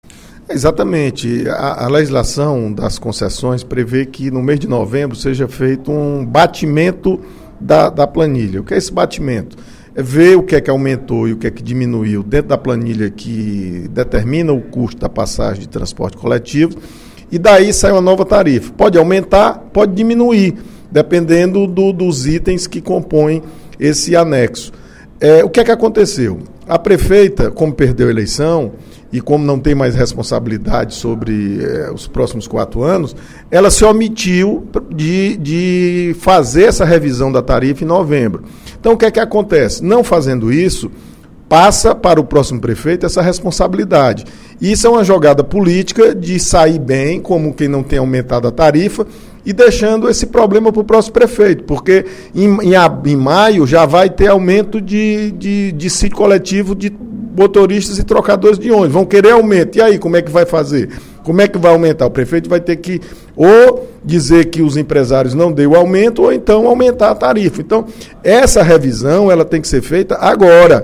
O deputado João Jaime (PSDB) afirmou, durante pronunciamento no primeiro expediente da sessão plenária desta quarta-feira (12/12), que é prevista em lei a revisão dos preços das passagens de ônibus urbanos de Fortaleza em novembro.